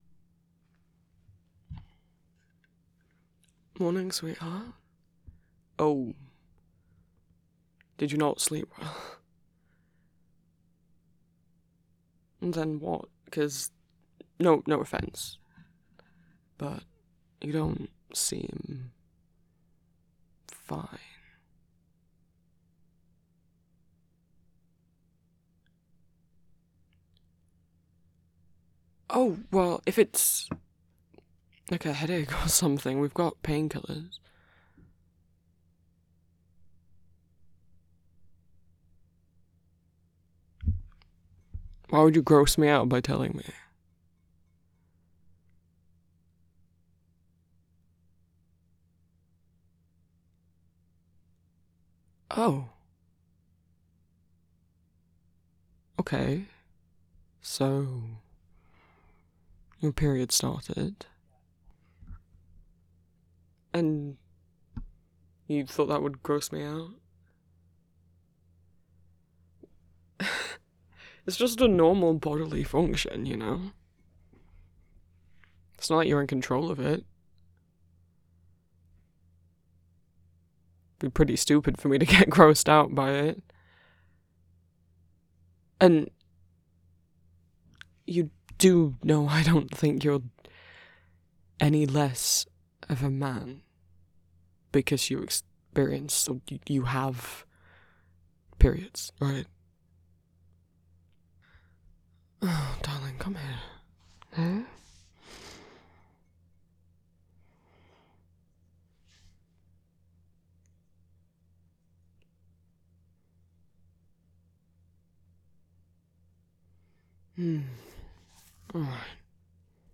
[M4TM] [Period comfort] [Romantic]